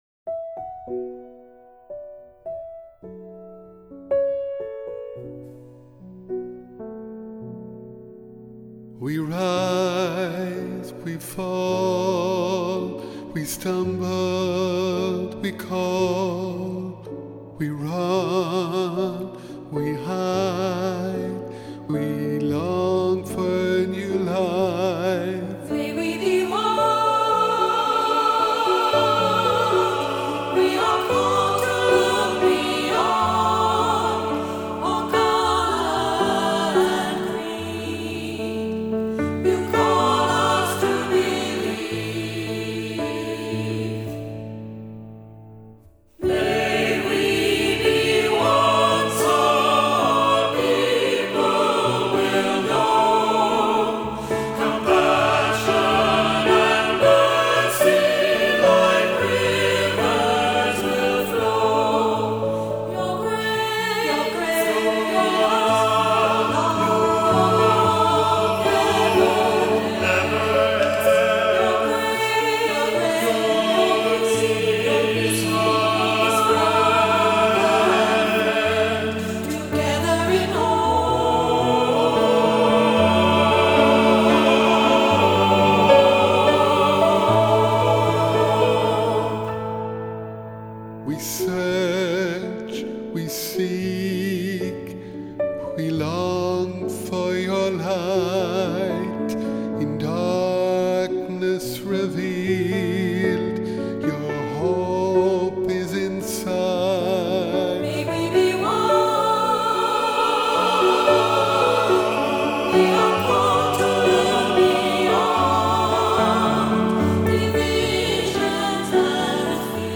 Voicing: "SATB","Cantor","Assembly"